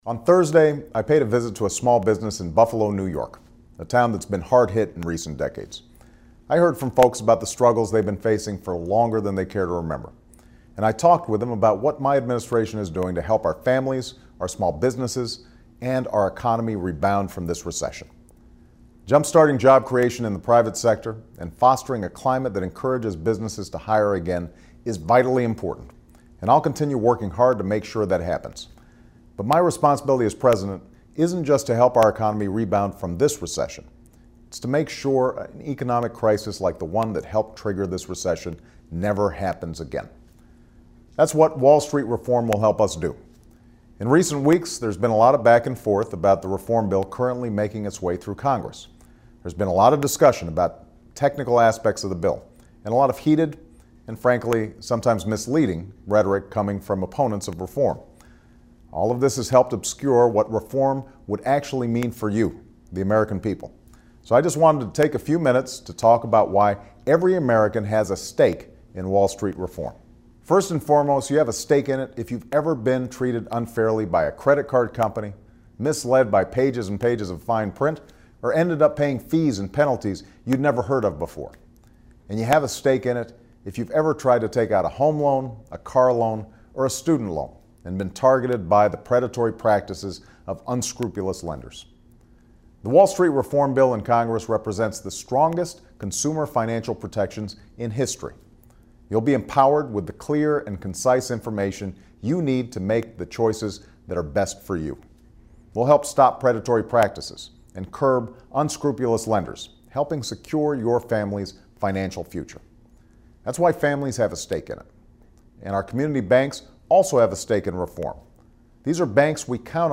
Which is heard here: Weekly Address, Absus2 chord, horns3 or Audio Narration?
Weekly Address